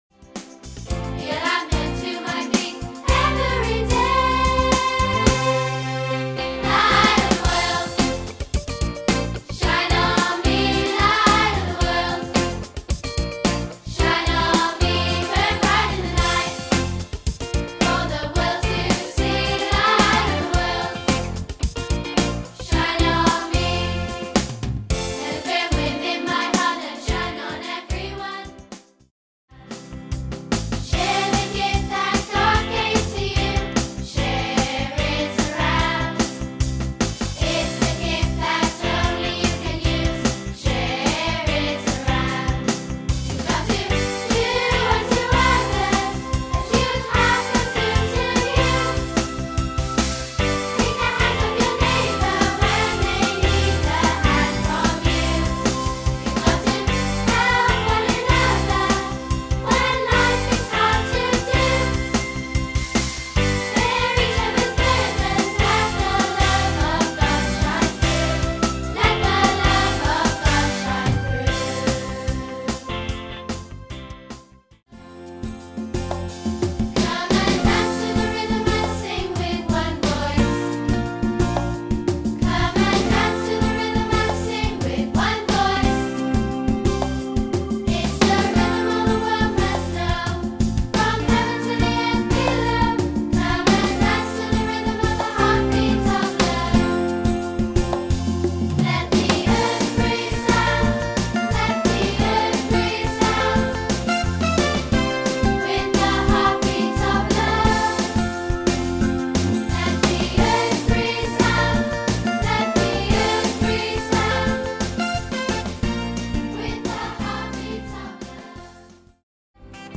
A collection of 15 new songs for schools.